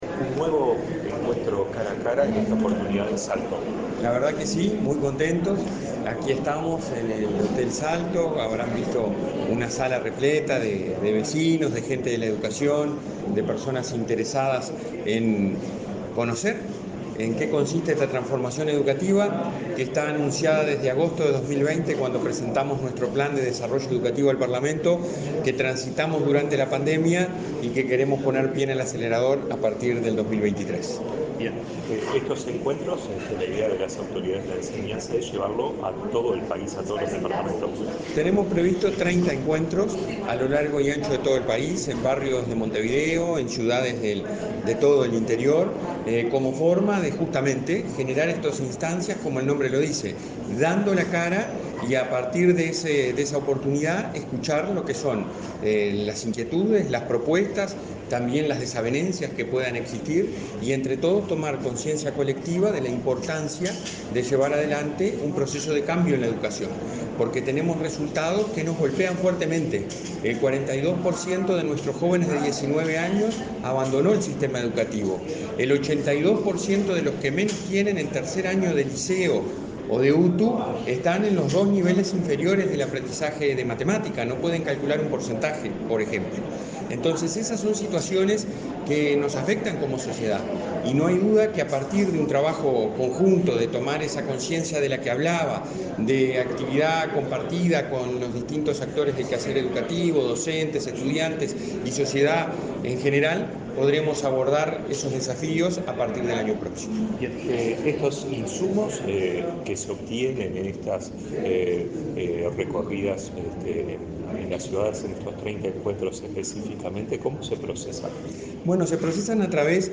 Entrevista al presidente del Consejo Directivo Central de la ANEP, Robert Silva
En la oportunidad, Silva realizó declaraciones a Comunicación Presidencial.